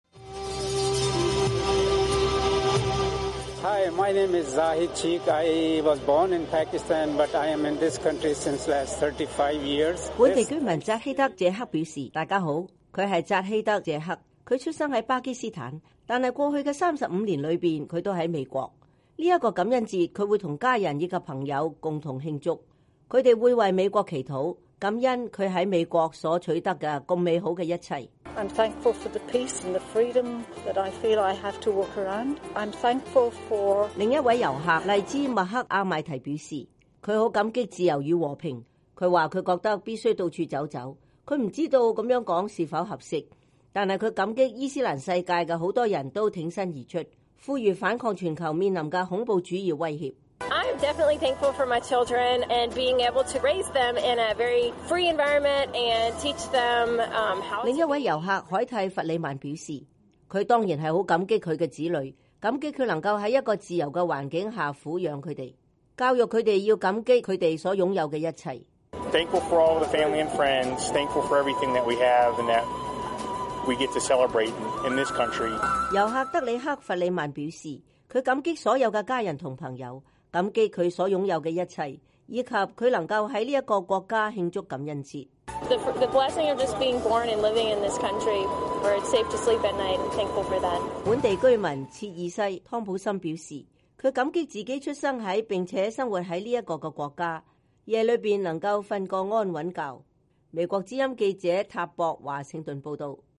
在感恩節即將來臨之際，美國之音記者在首都華盛頓街頭採訪了一些居民和遊客。今年，由於巴黎恐怖襲擊，人們的情緒中充滿了反思和感恩。